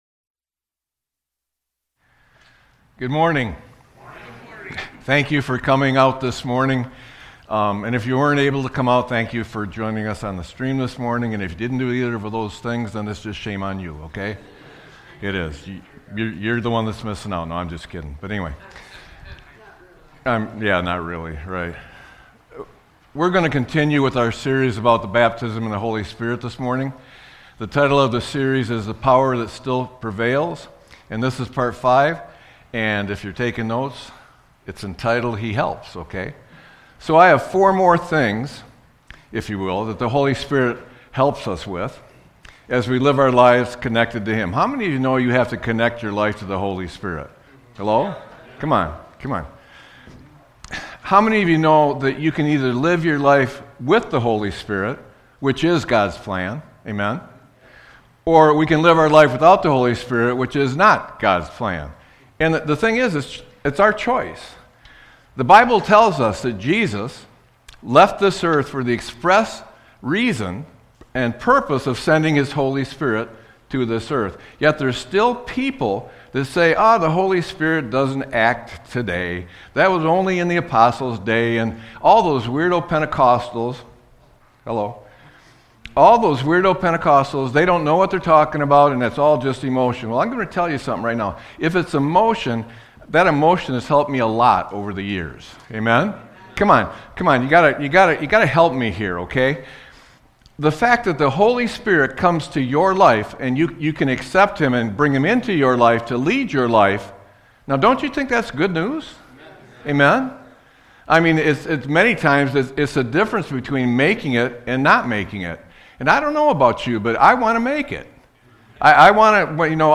Sermon-3-08-26.mp3